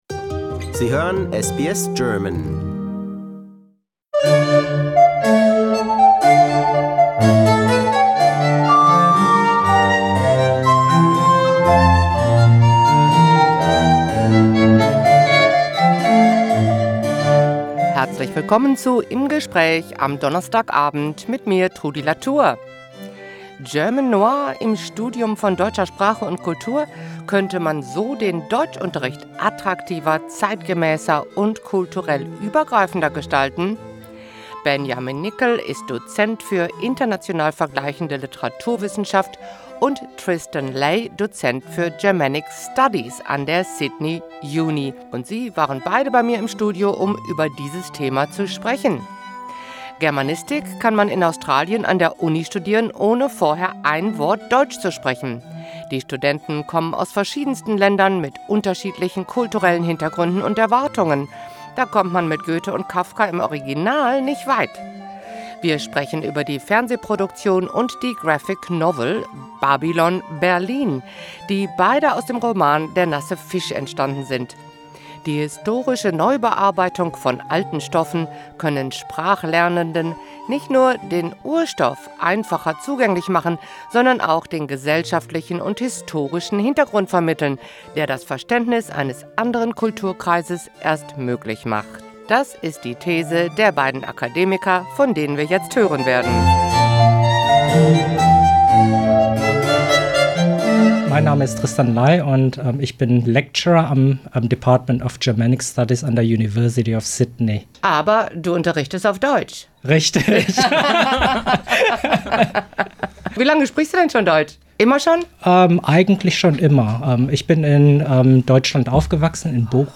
In conversation: Babylon Berlin in German courses 1/2